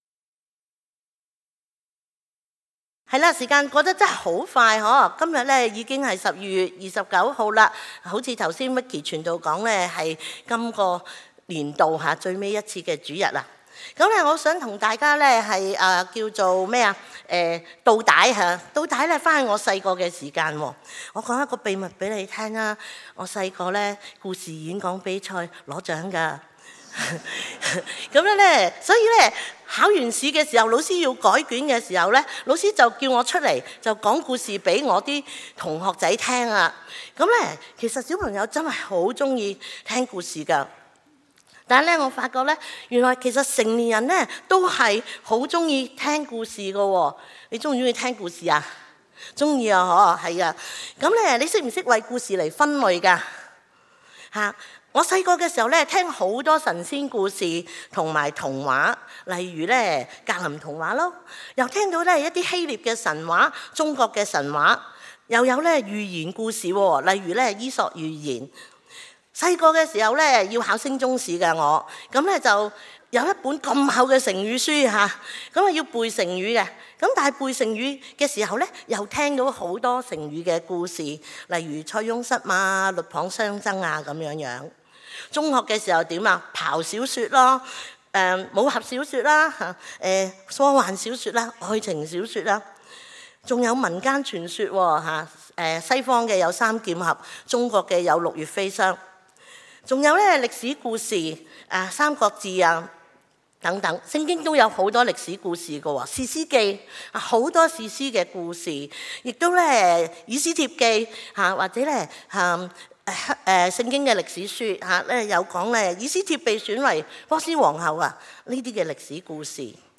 主耶穌基督講比喻9 恩福中心星期六早堂
證道重溫